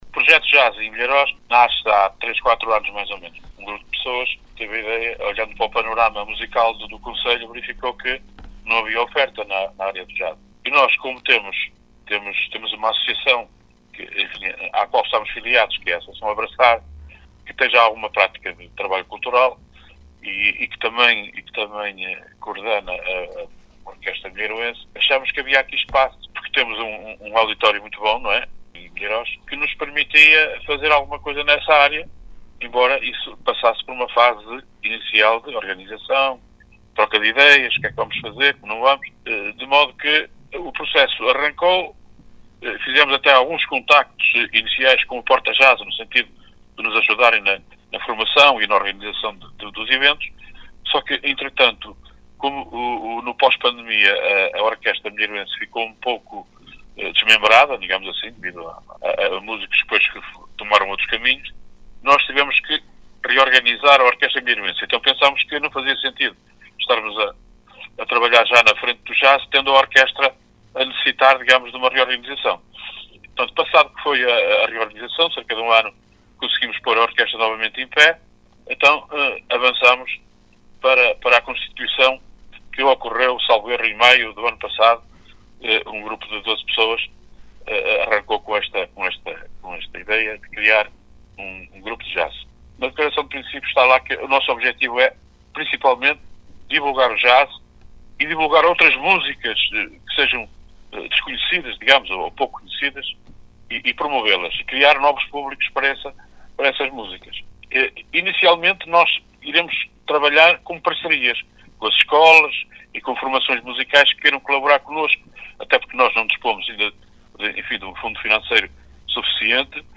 AtualidadeCulturaEntrevistasNotíciasRegistos